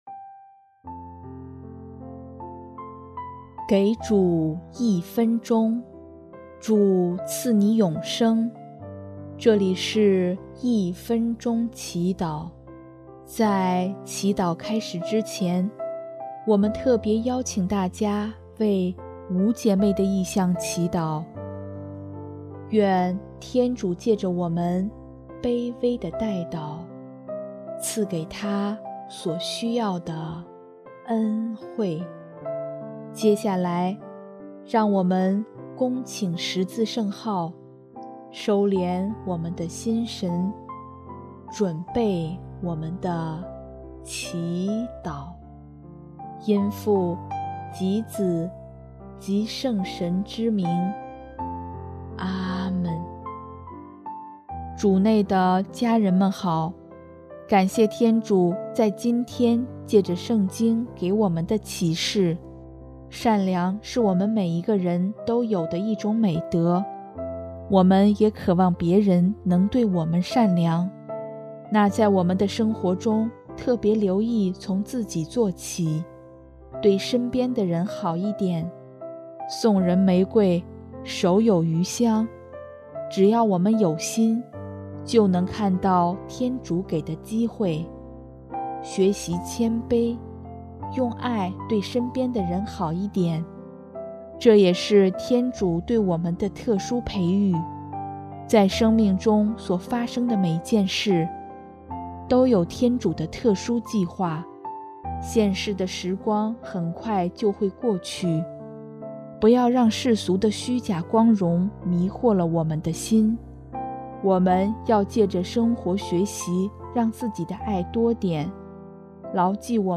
【一分钟祈祷】|9月21日 学习让自己成为天主爱的标记
音乐： 第二届华语圣歌大赛参赛歌曲《我们的耶稣》